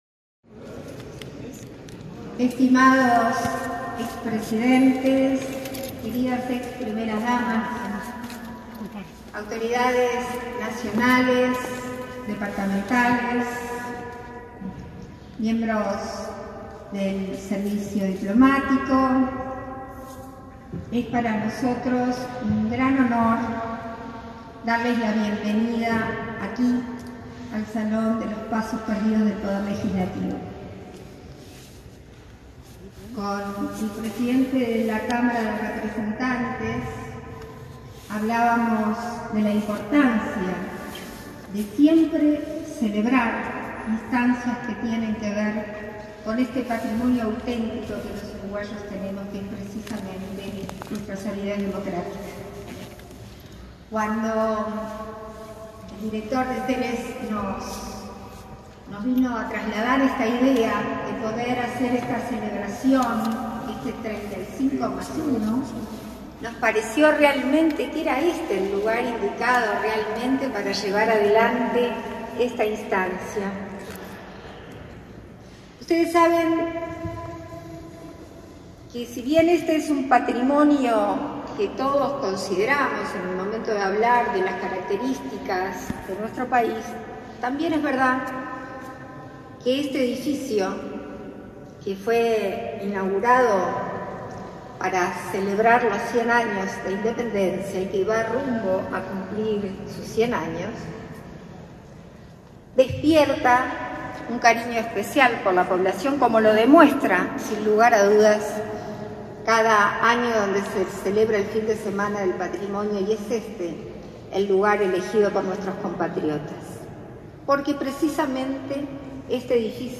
Conferencia de prensa por el lanzamiento del homenaje de Ceres a la democracia uruguaya
Conferencia de prensa por el lanzamiento del homenaje de Ceres a la democracia uruguaya 27/08/2021 Compartir Facebook X Copiar enlace WhatsApp LinkedIn En el marco de los festejos del 36.º aniversario de la democracia uruguaya, se realizó el lanzamiento del homenaje del Centro de Estudios de la Realidad Económica y Social (Ceres) este 26 de agosto en el Palacio Legislativo.